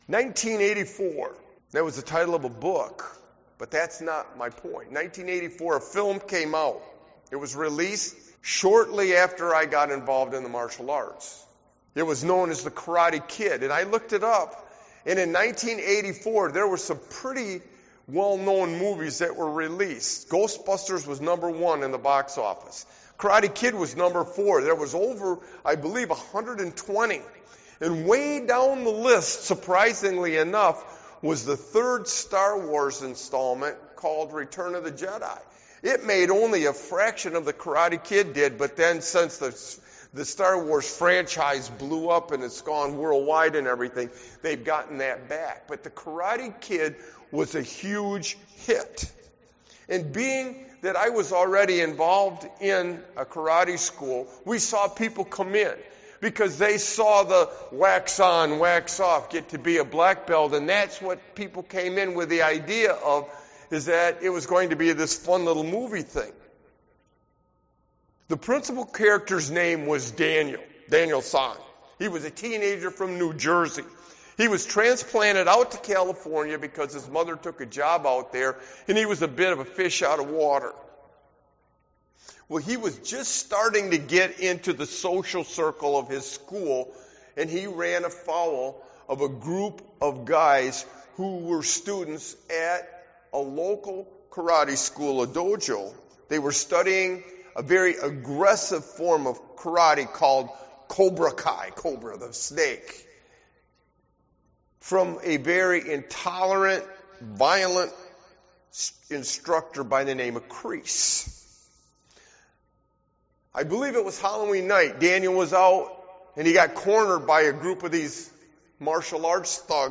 Sermon Walking with Jesus through Difficulty and Loss 5